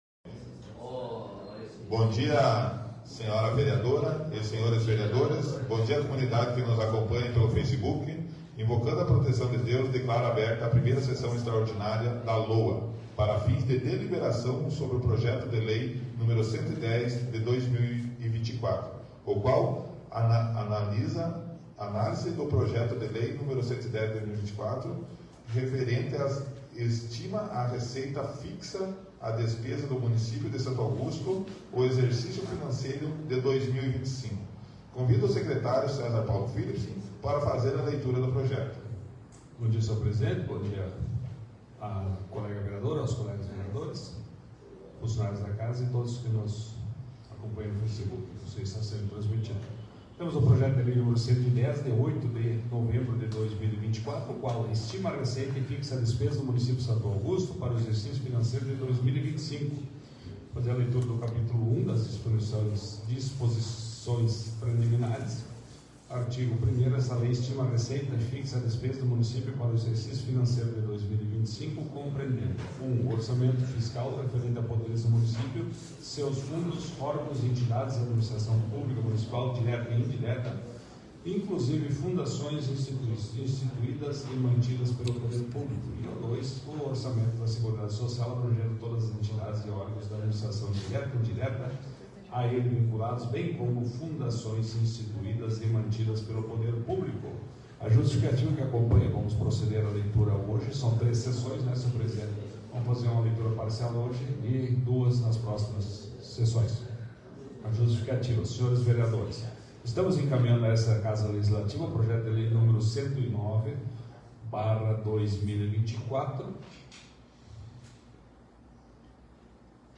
8ª Extraordinária da 4ª Sessão Legislativa da 15ª Legislatura